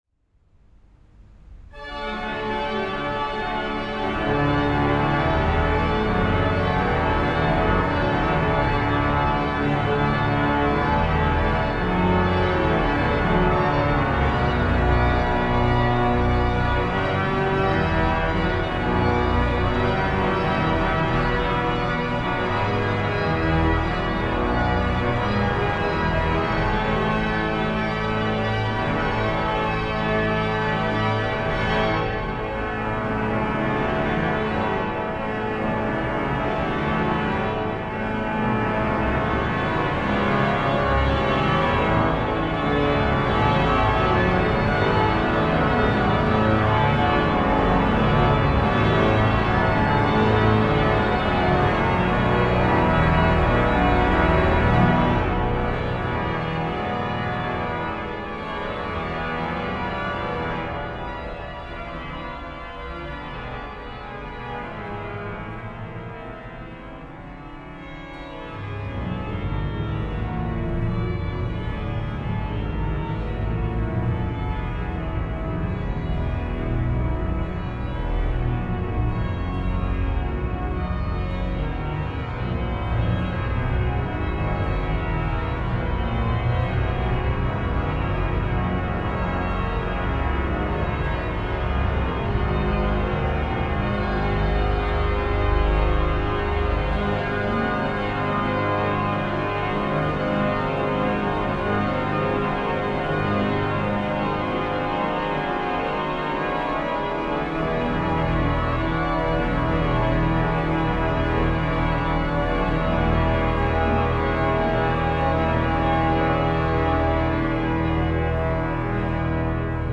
An der Schijven/Van Bever-Hauptorgel, Laken (B)